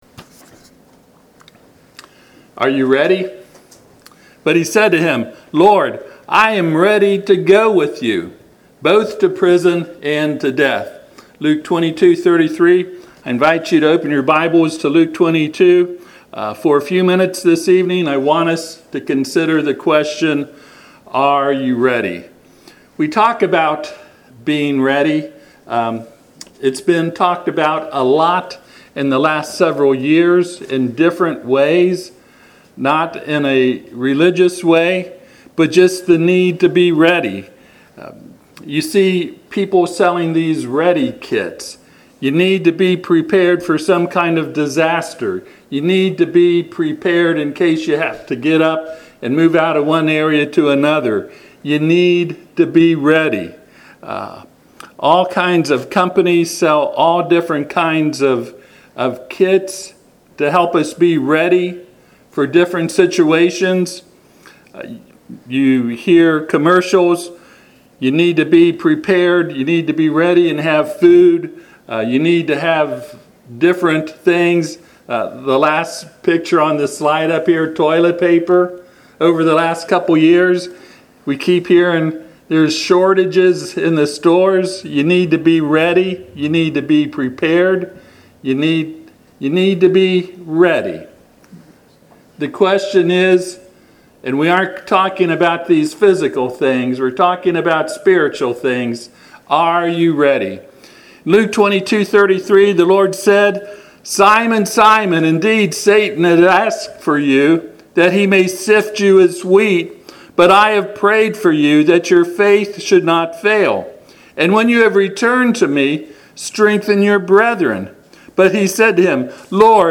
Luke 22:33 Service Type: Sunday PM https